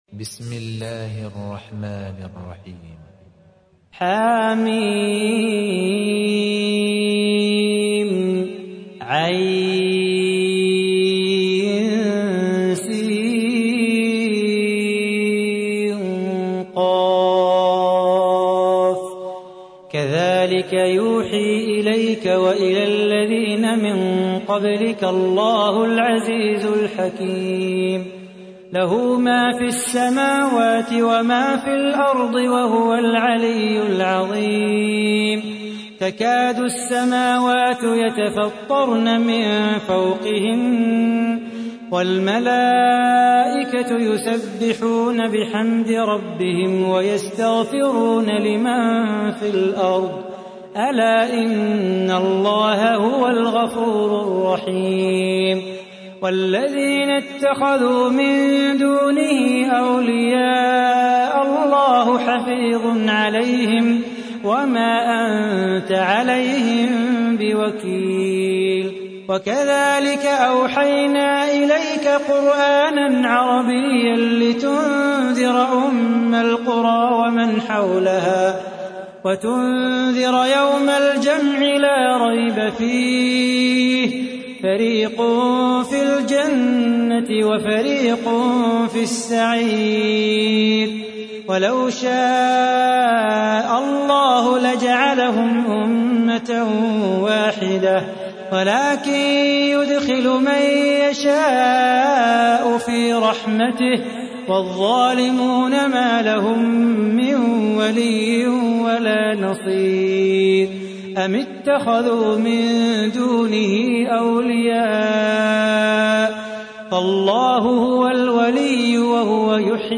تحميل : 42. سورة الشورى / القارئ صلاح بو خاطر / القرآن الكريم / موقع يا حسين